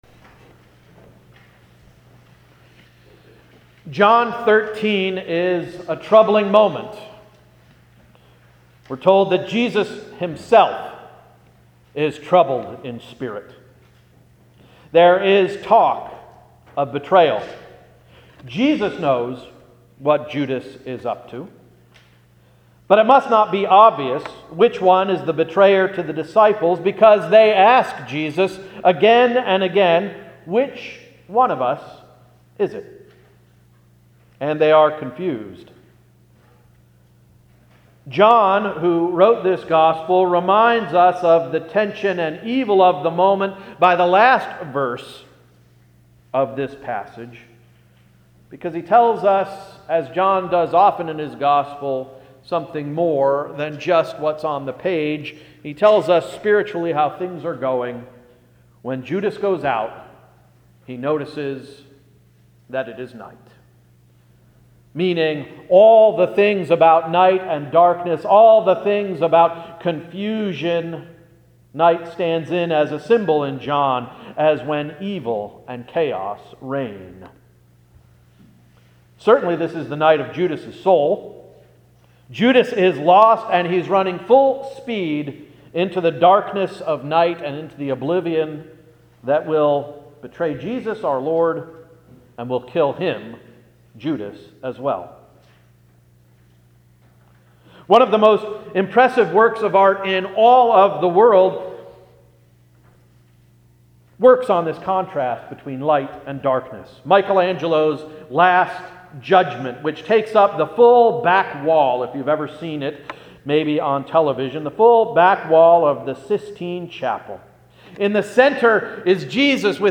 March 18 Sermon — “Abstain from Evil”